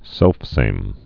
(sĕlfsām)